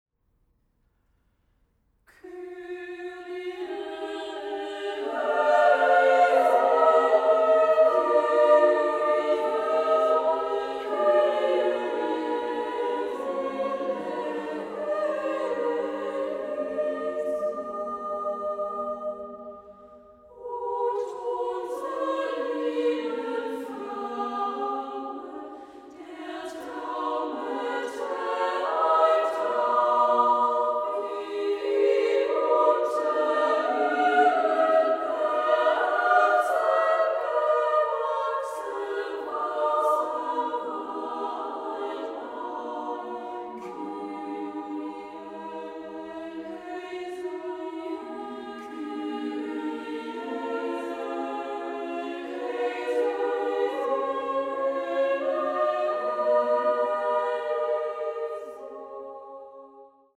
Works for Women’s and Children’s Choir and Solo Songs